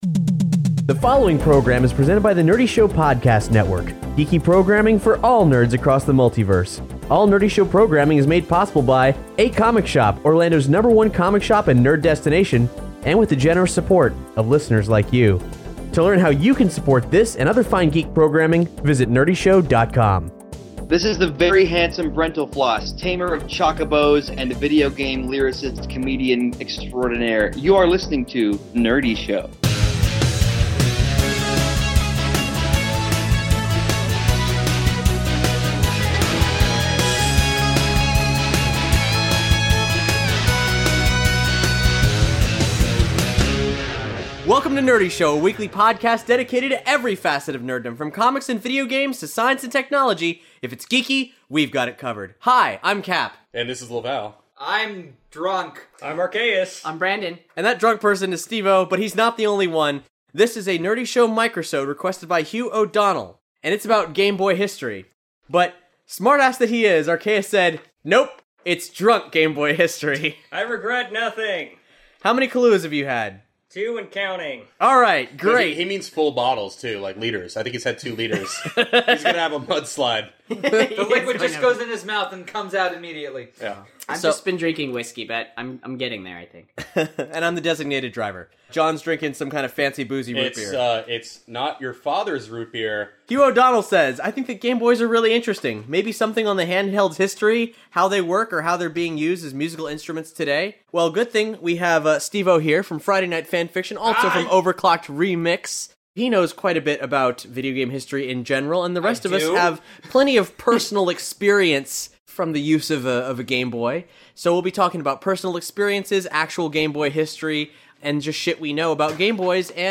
These amateur video game historians are drunk!